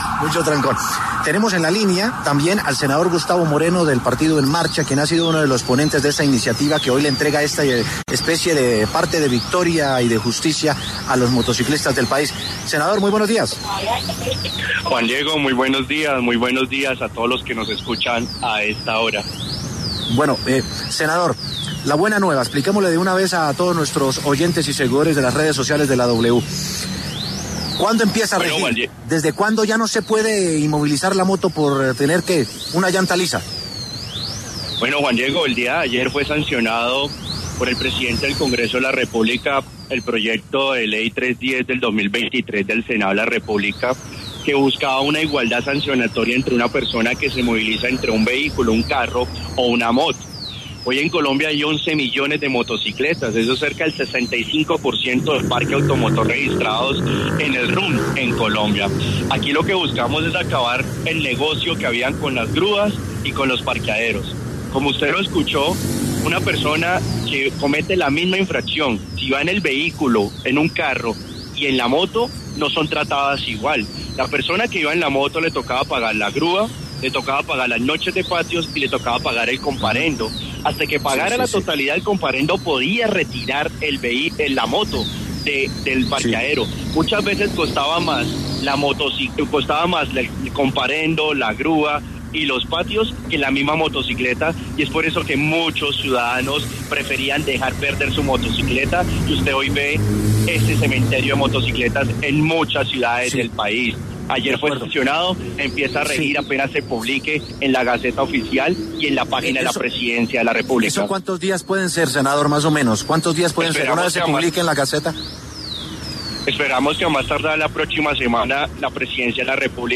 Gustavo Moreno, senador del partido En Marcha, ponente de esta nueva ley, habló para La W y detalló que lo que se busca es acabar con el negocio de las grúas y los parqueaderos, una persona que comete la misma infracción en el carro no es tratada igual que la que va en moto”.